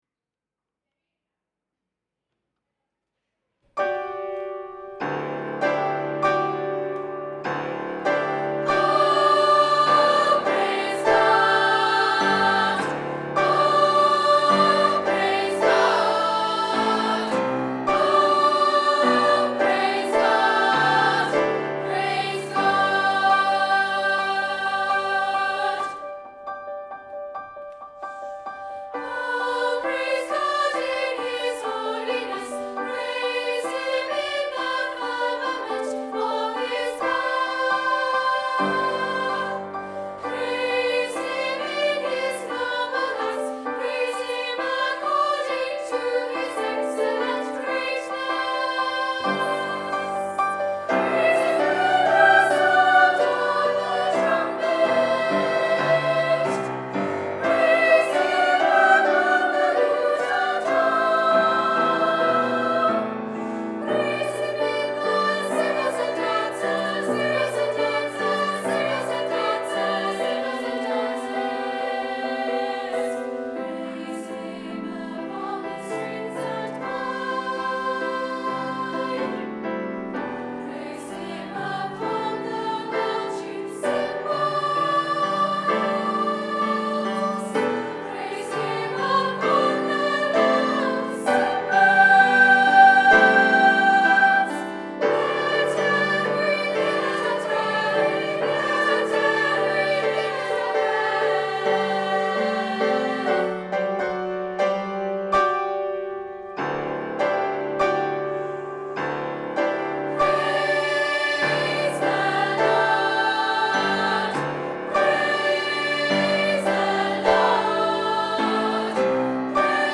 Psalm 150 Chilcott - Songs of Praise - Audition(1).mp3